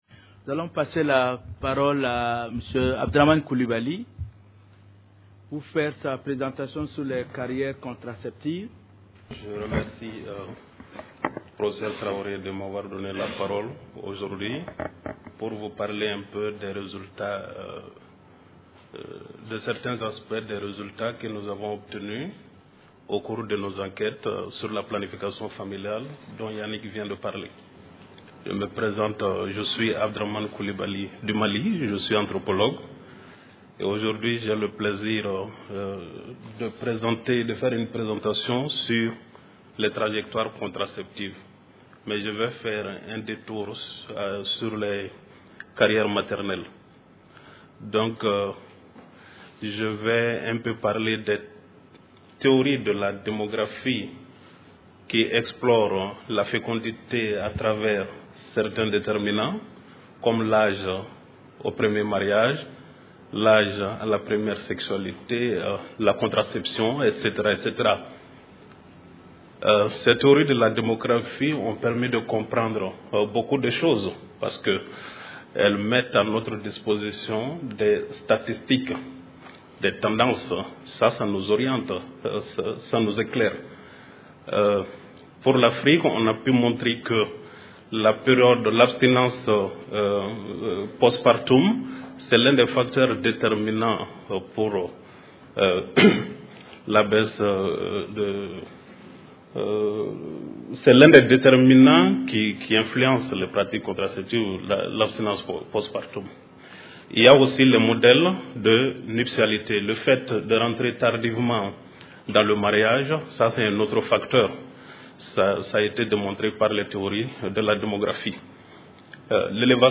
Réduction de la mortalité maternelle – Carrières maternelles et trajectoires contraceptives des femmes au Mali. Conférence enregistrée dans le cadre du Colloque International Interdisciplinaire : Droit et Santé en Afrique.